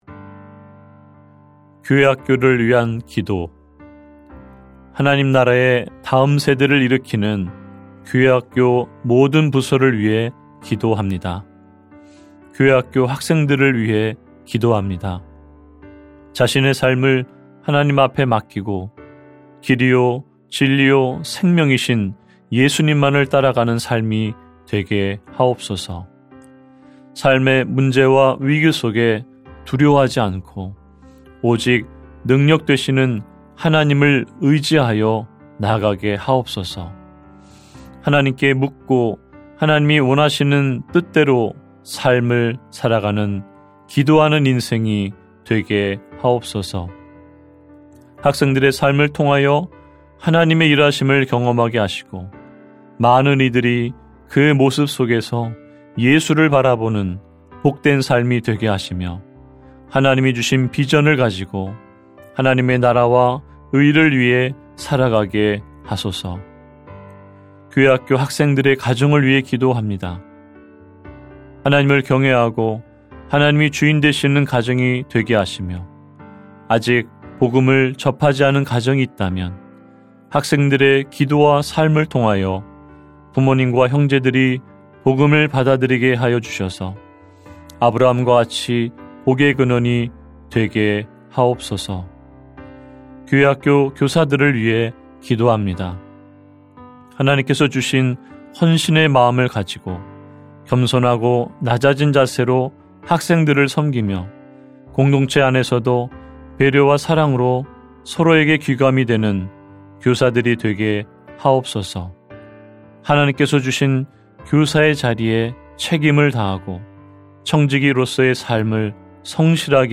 중보기도